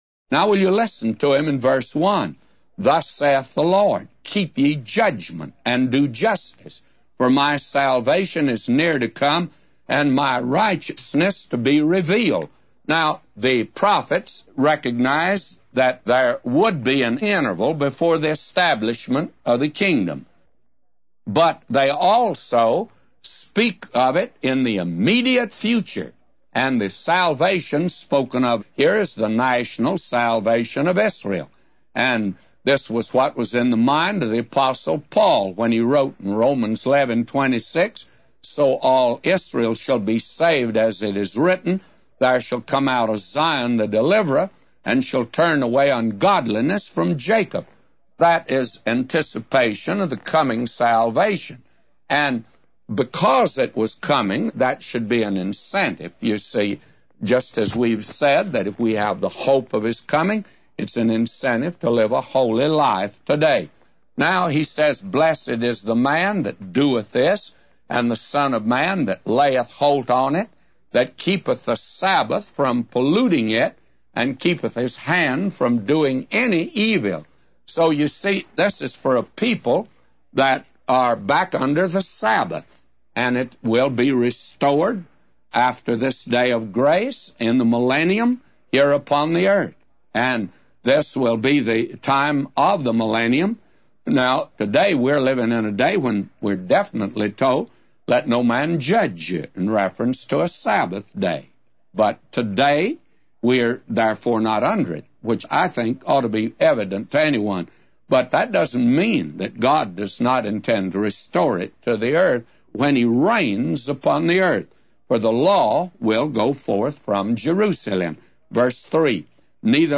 A Commentary By J Vernon MCgee For Isaiah 56:1-999